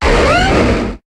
Cri de Carchacrok dans Pokémon HOME.